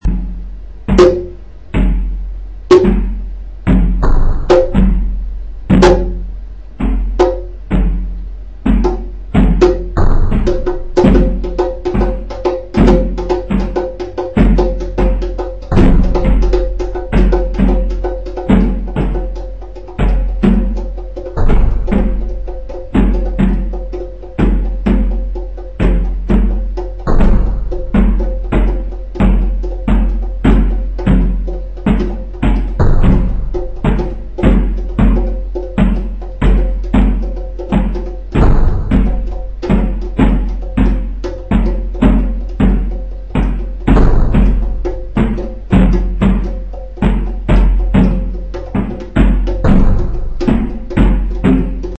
Sound Portrait
INSTRUCTIONS: Create an audio file which sounds like you, without speaking.